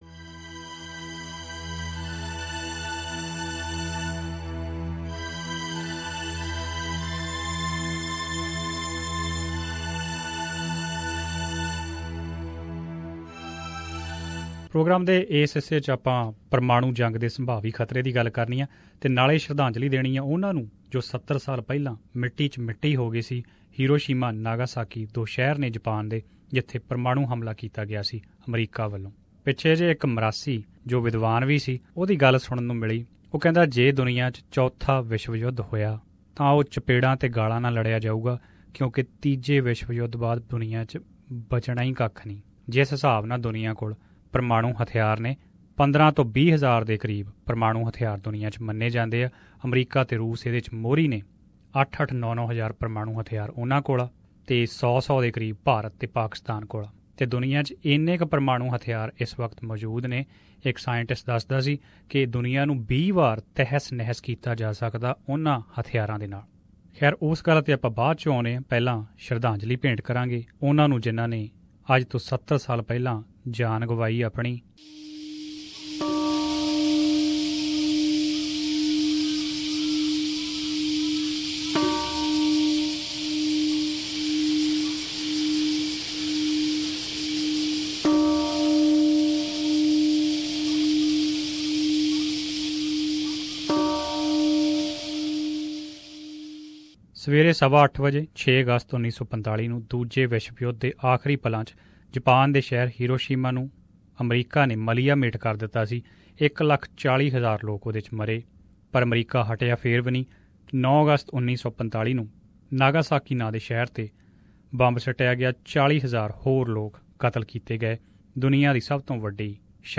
SBS Punjabi